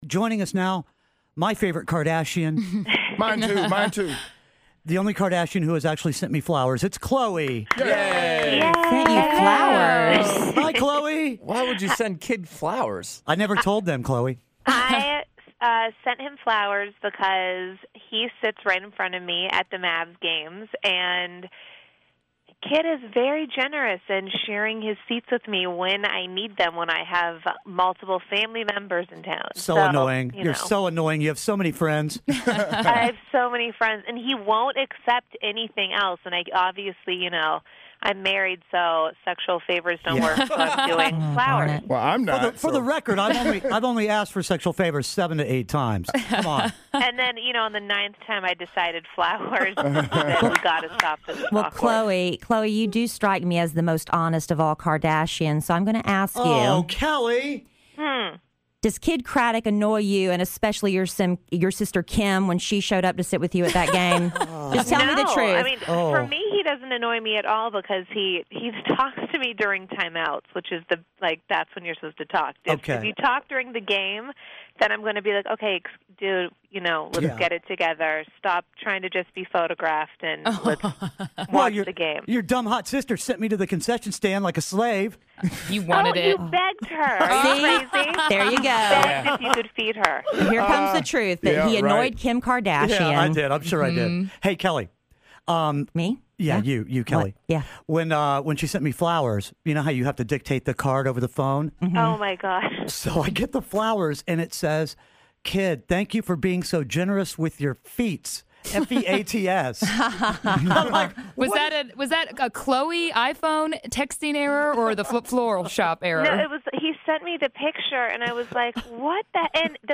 Khloe Kardashian Interview
Kidd Kraddick in the Morning interviews Khloe Kardashian!